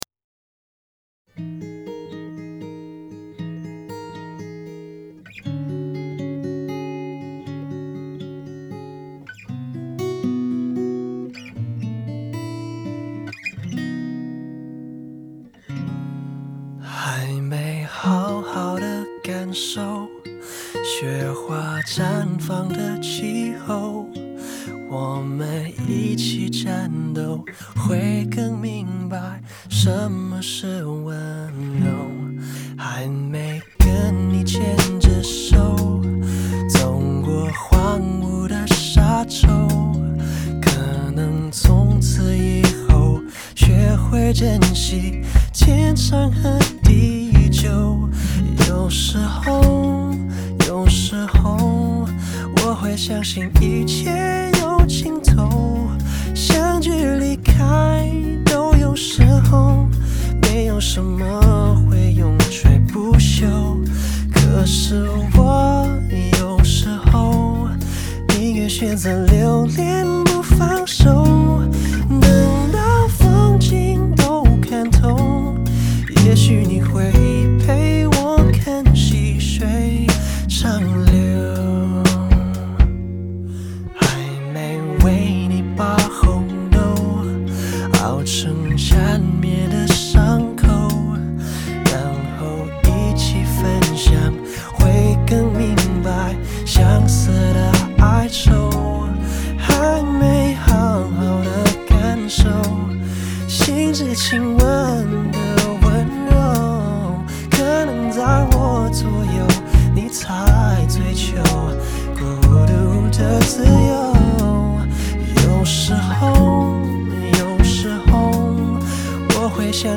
类别: 电音